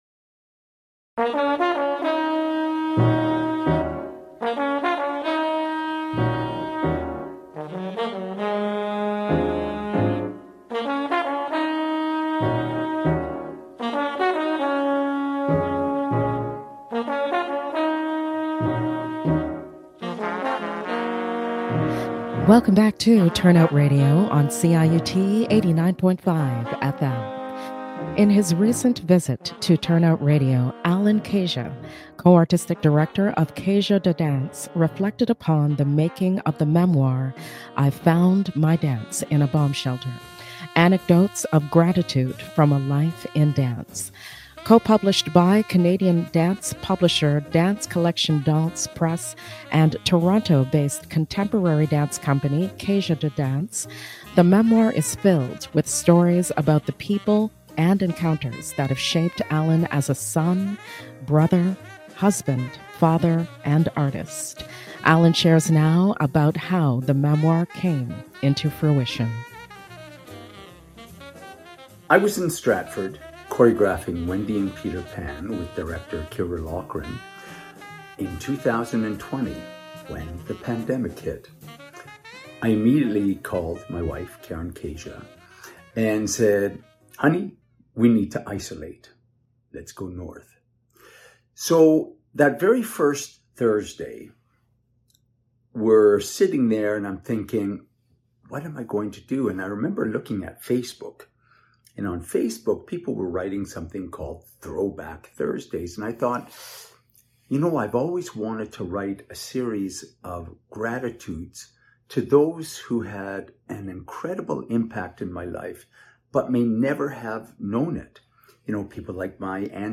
Live on CIUT 89.5 FM